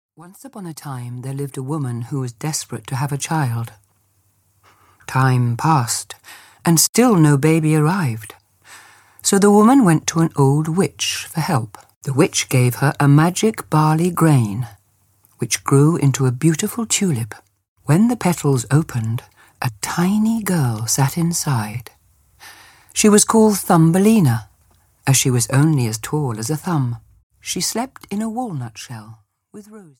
Thumbelina (EN) audiokniha
Ukázka z knihy
• InterpretCharlotte Rampling